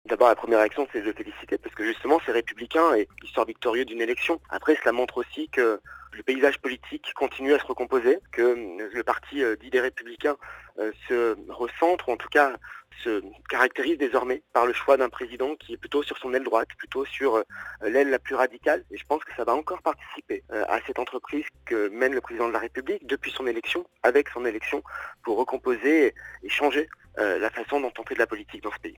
Voici quelques extraits de cet entretien téléphonique, réalisé en direct.